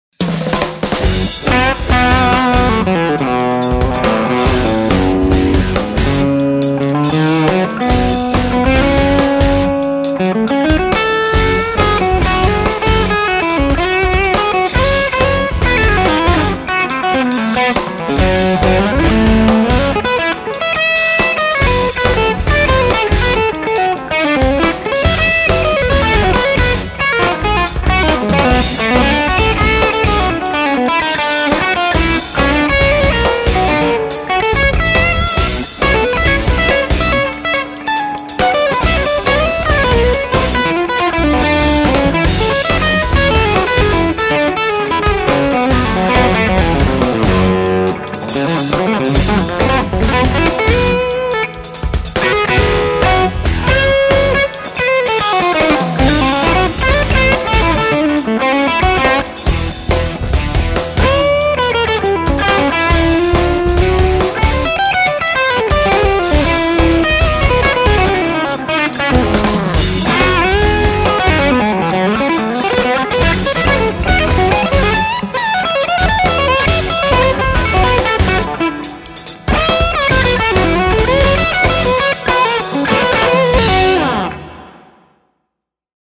(mids at 10.1k, 1uf on V2b)